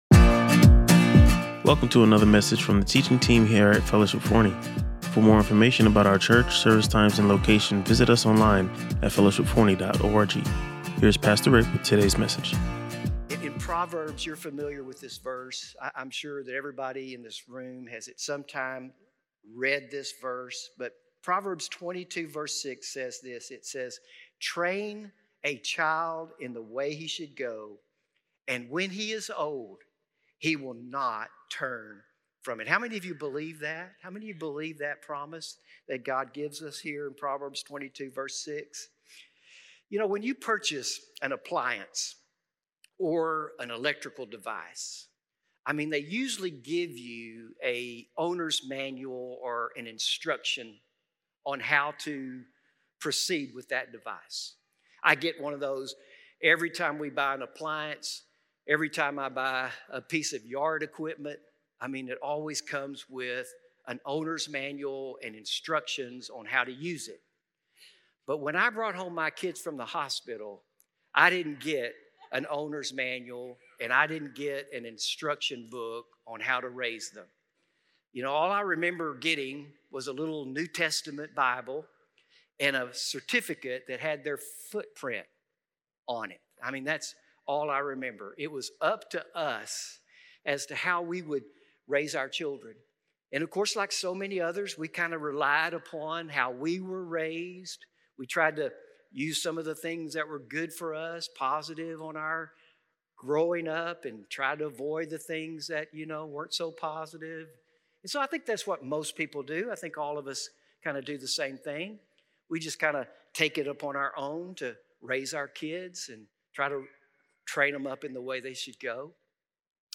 Listen to or watch the full sermon and discover more insights on God’s blueprint for successful parenting.